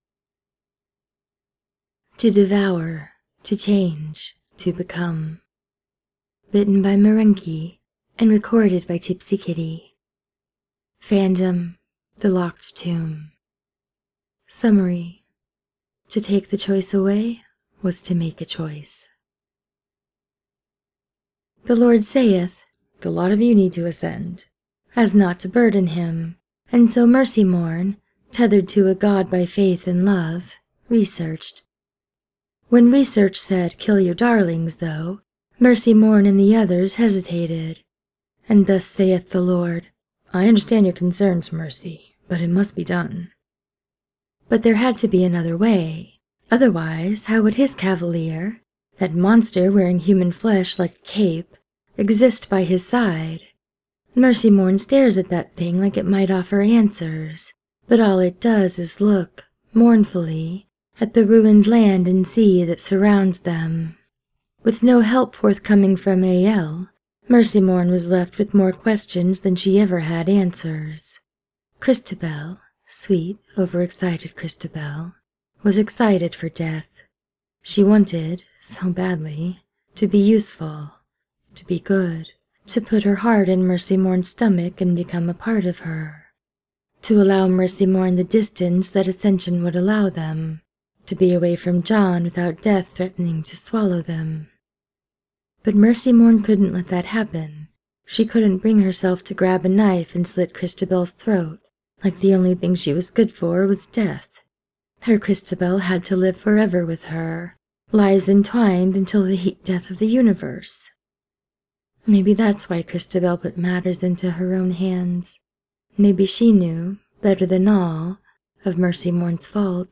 without music: